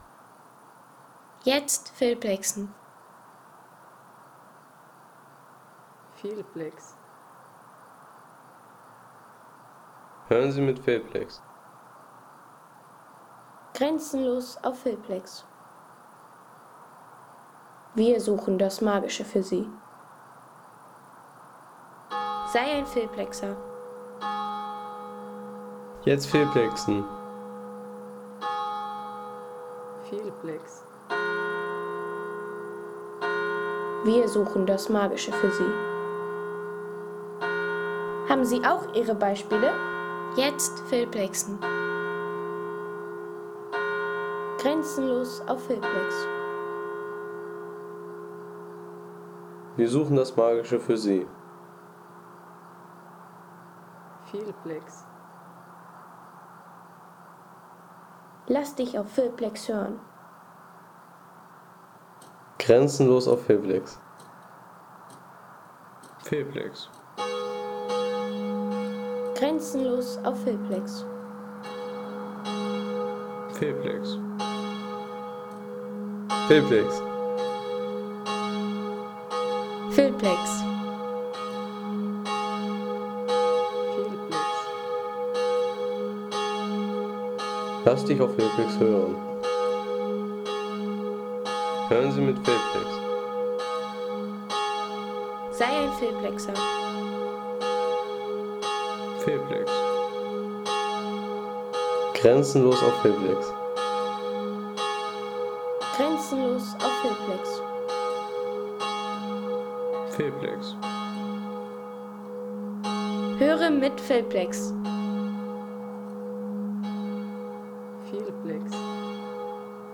Glockengeläut aus Pinswang | Feelplex
Wenn Kirchenglocken gleich das ganze Tal mitnehmen
Authentisches Glockengeläut der Pfarrkirche St. Ulrich in Pinswang mit ruhiger Talatmosphäre und hörbarem Uhrwerk.